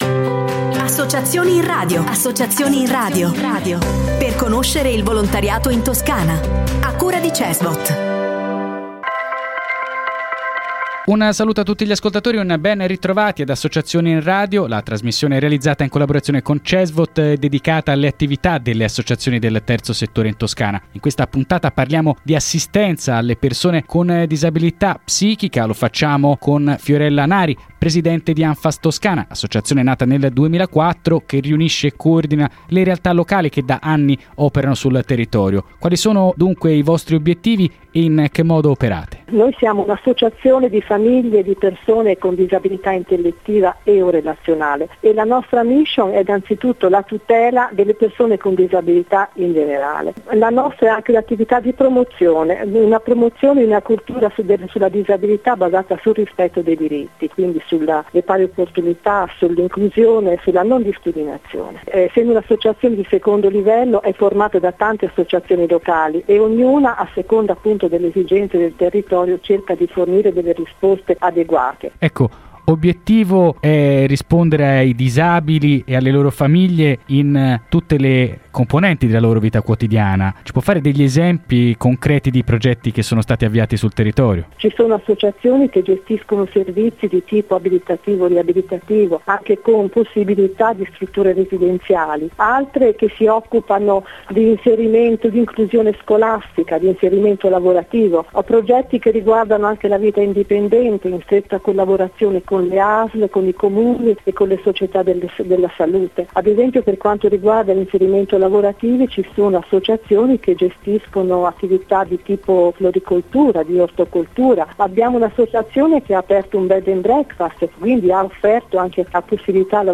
Anche questa settimana sono tante le inziative del volontariato che sentirete sulle principali radio toscane. Tre, infatti, le rubriche promosse da Cesvot, in collaborazione con Controradio, Contatto Radio, Radio Toscana, Novaradio: Associazioni in radio, Volontariato in onda e Agenda Cesvot.